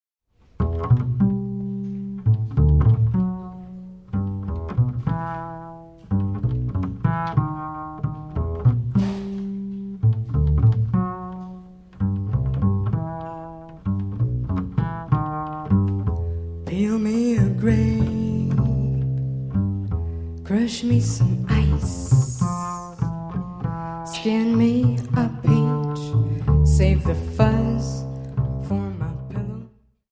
Gesang
Piano
Kontrabass
Schlagzeug